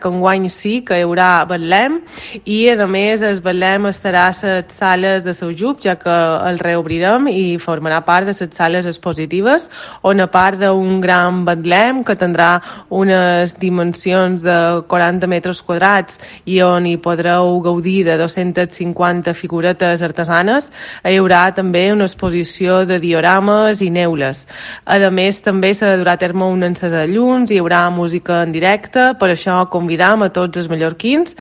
Antònia Roca, Vicepresidenta y consellera insular de Cultura i Patrimoni